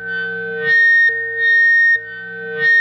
PRS FBACK 10.wav